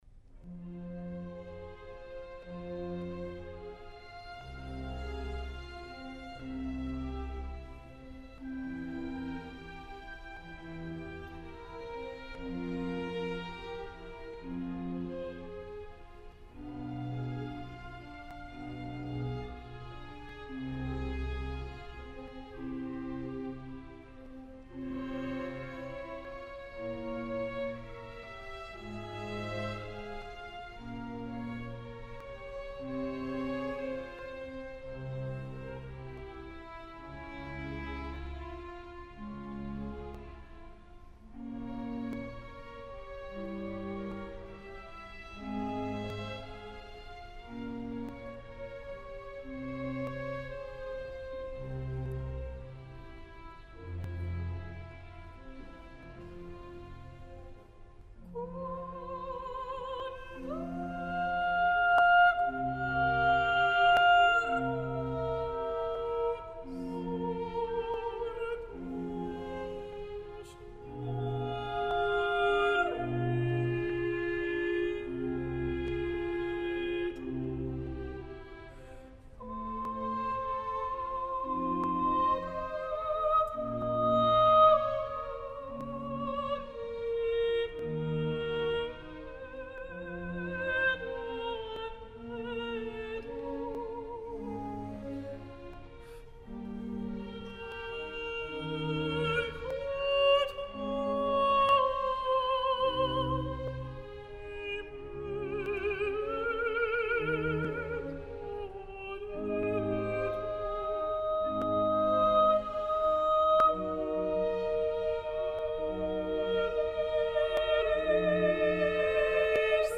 L’obra va ser escrita per a dos castrats, orquestra de corda i continuo.
us vull fer escoltar el preciós duet “Quando corpus morietur”, una perfecte simbiosi de patetisme i lirisme que em serveix per presentar-vos a les dues solistes d’aquesta bellíssima versió
soprano
Nathalie Stutzmann (coltralto)
BBC Symphony Orchestra
Director: Marc Minkowski